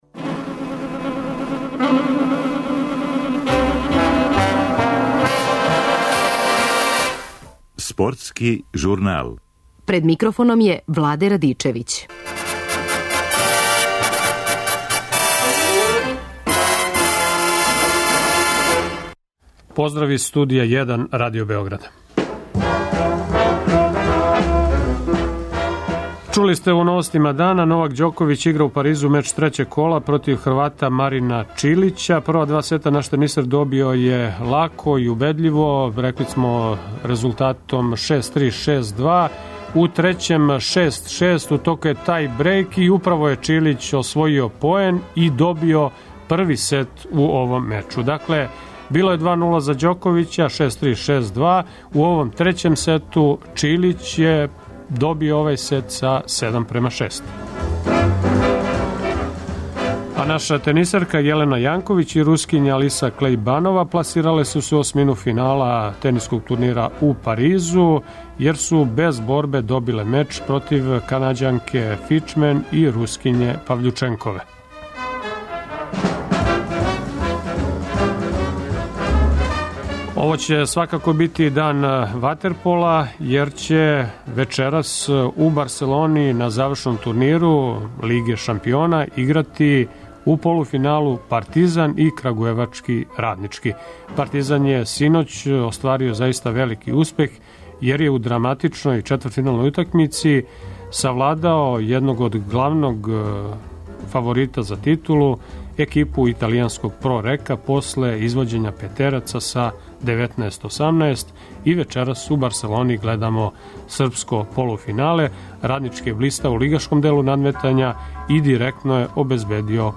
Наш специјални извештач јавља нам каква је атмосфера у Баселони уочи важне утакмице.
Из Чикага ћемо чути и капитена фудбалске репрезентације Бранислава Ивановића.